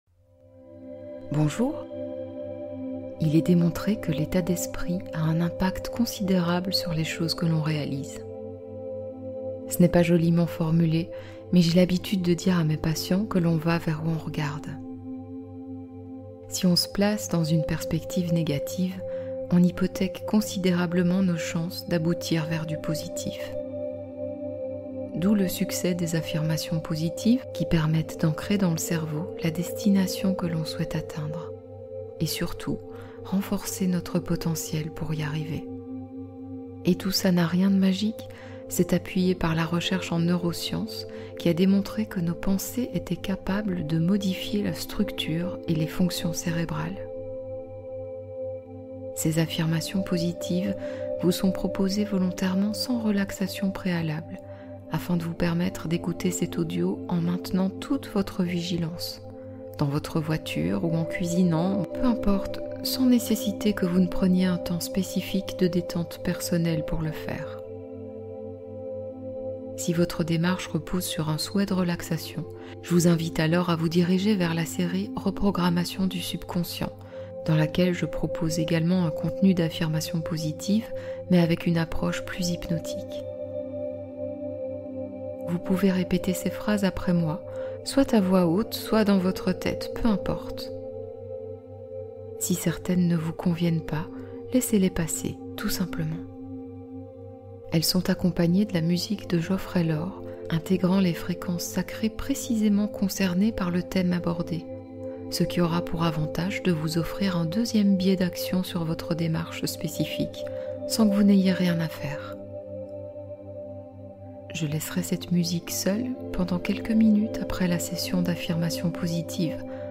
Dépendance affective : affirmations et fréquences guérissantes